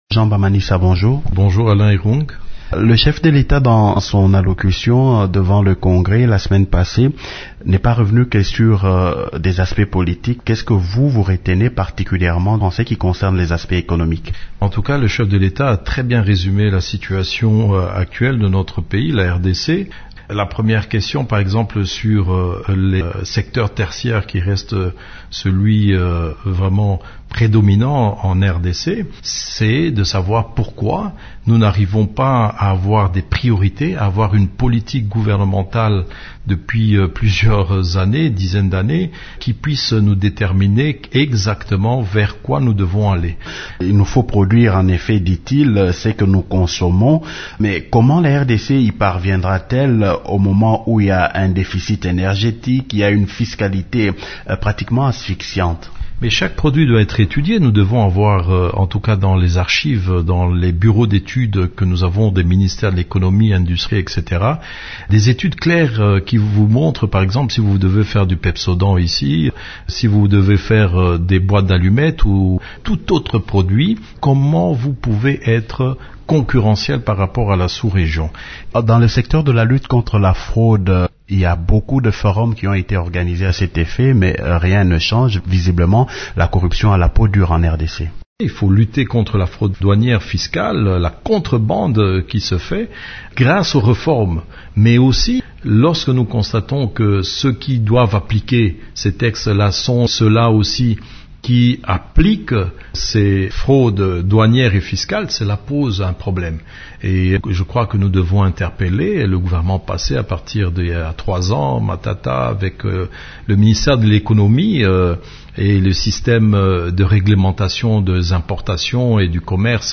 L’ancien gouverneur de la Province Orientale, Jean Bamanisa, est l’invité du journal du soir e Radio Okapi.